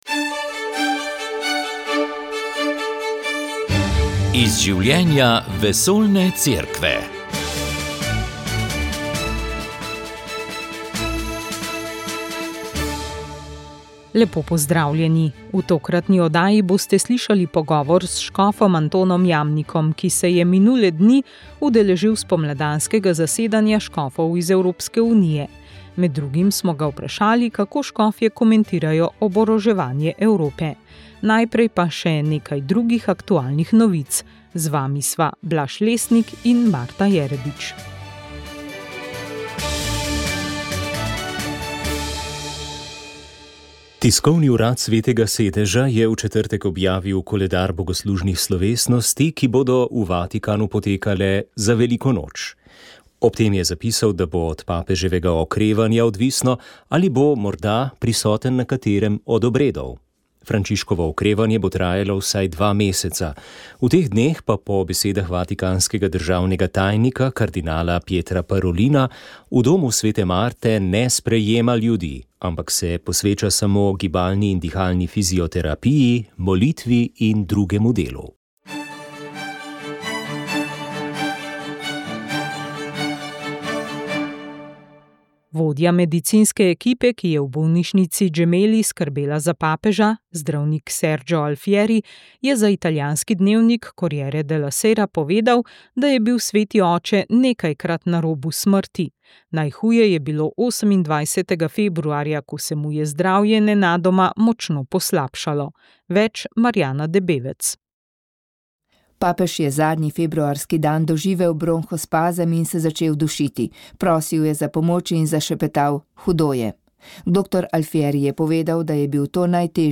Na univerzi Sigmunda Freuda so 11. marca pripravili okroglo mizo z naslovom Žalovanje in paliativna oskrba.